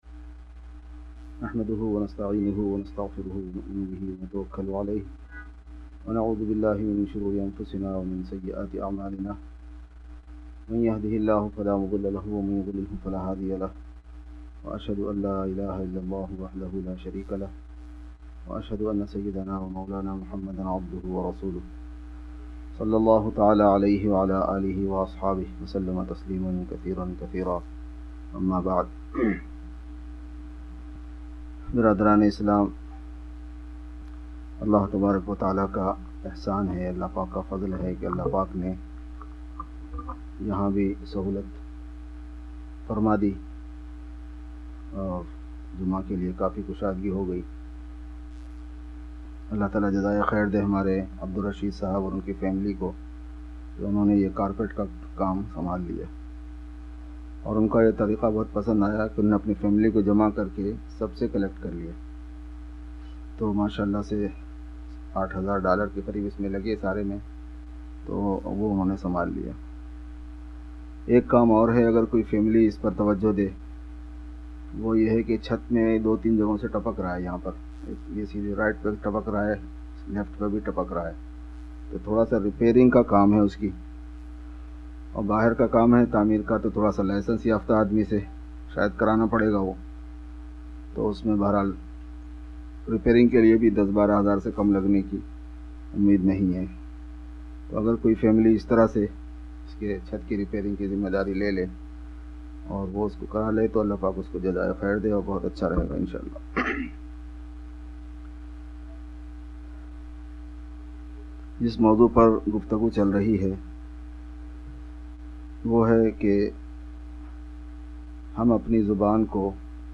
Khutbat e Juma